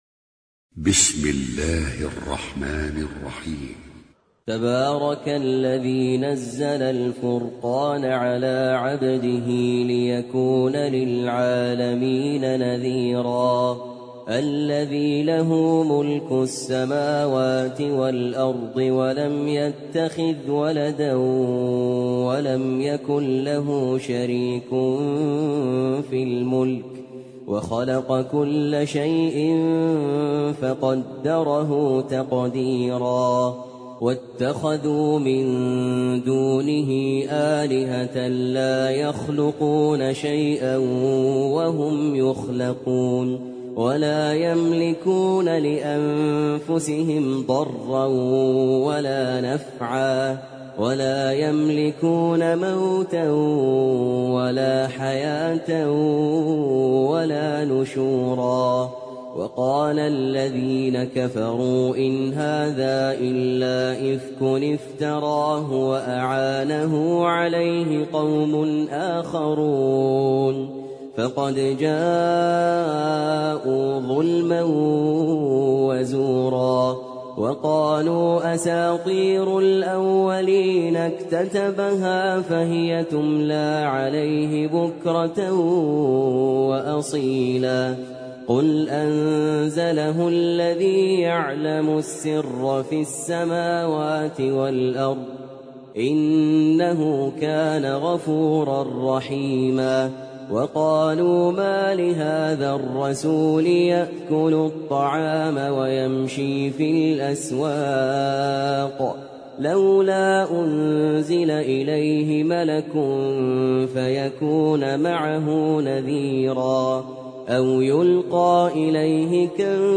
Sûrat Al-Furqan (The Criterion) - Al-Mus'haf Al-Murattal
high quality